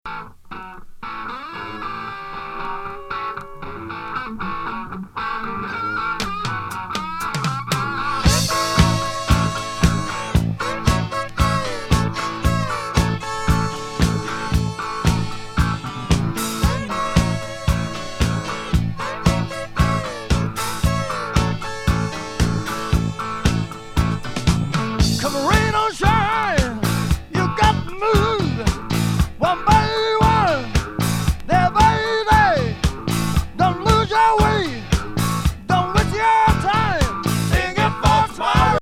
骨太ファンキー・ディスコ・ナンバーからレゲーまで！